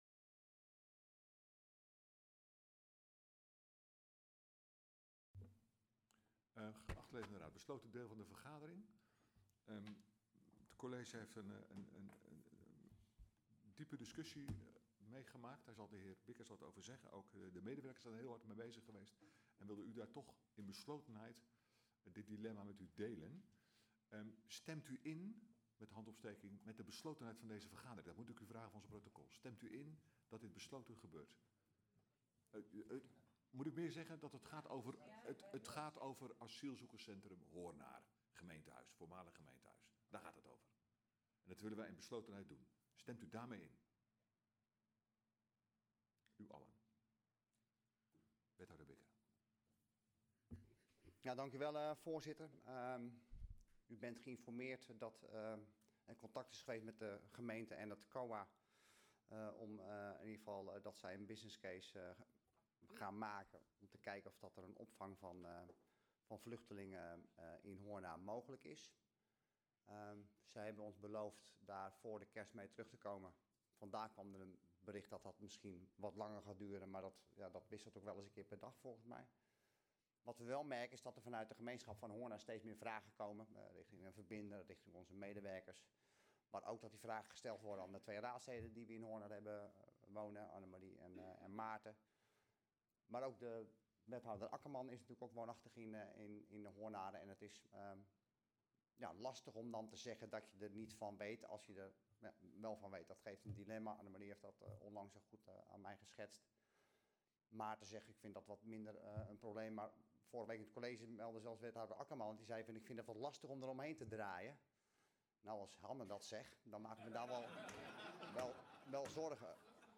Agenda Molenlanden - Raadsvergadering dinsdag 10 december 2024 19:30 - 23:59 - iBabs Publieksportaal